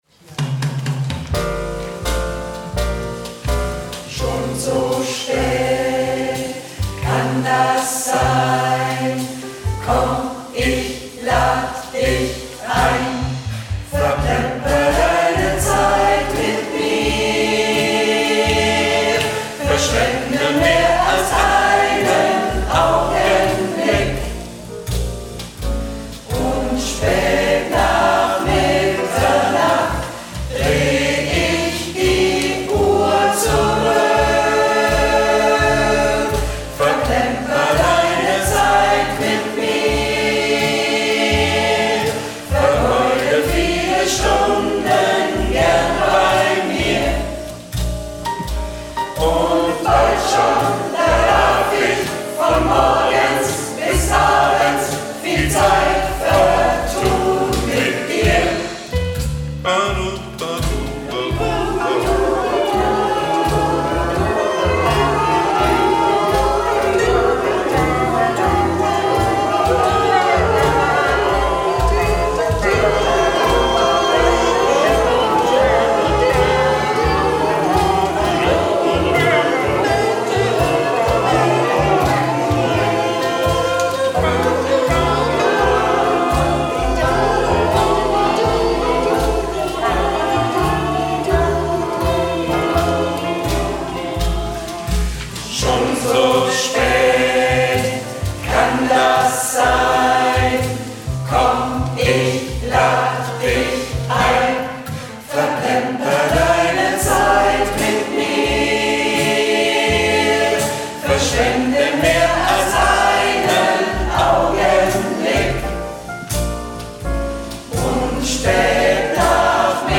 Workshop 2025
Aufnahmen von den Proben